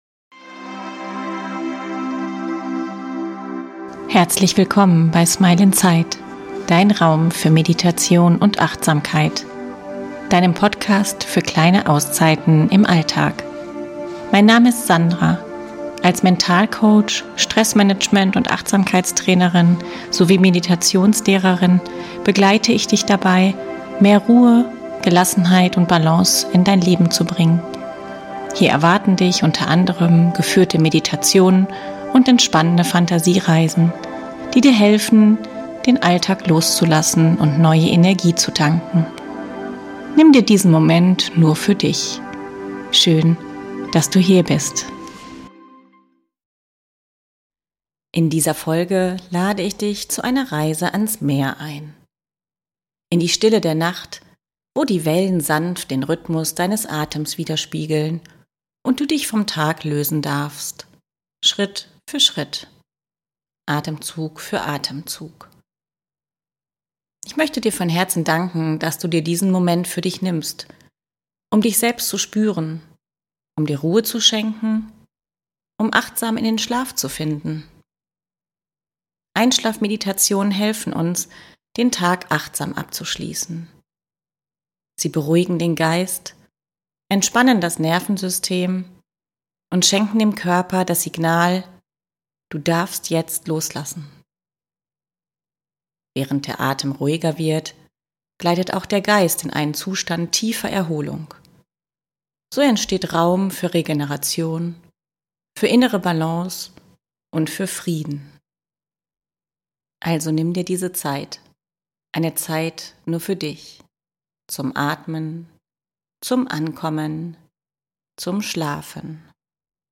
Das Meer bei Nacht - Meditation zum Einschlafen ~ Smile inside - Dein Raum für Meditation und Achtsamkeit Podcast
Während du dich vom Klang des Meeres tragen lässt, findet dein ganzer Organismus zurück in Balance, in Ruhe – und in Frieden. Diese Meditation ist eine Einladung, den Tag liebevoll zu verabschieden und dich in die Stille der Nacht hineinfallen zu lassen. Lehn dich zurück, lausche den Wellen, und lass dich vom Meer in einen tiefen, erholsamen Schlaf wiegen.